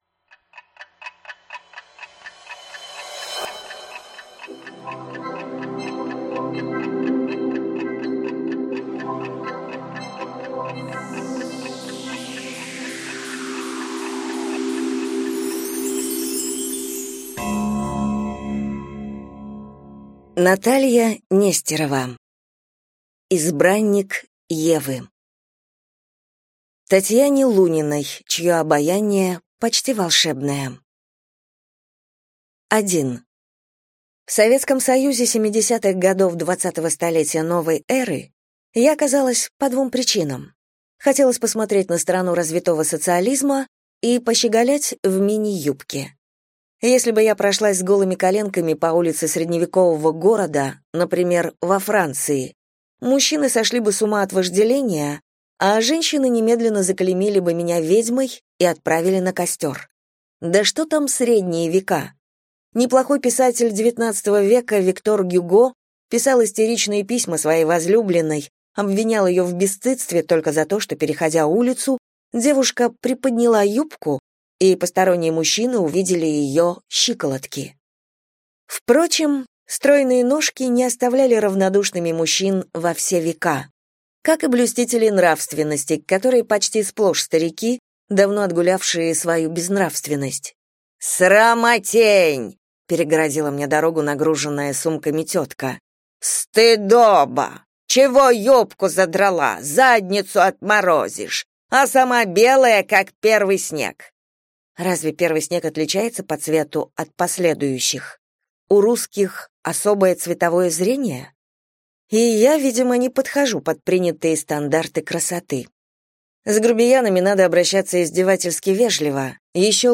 Аудиокнига Избранник Евы | Библиотека аудиокниг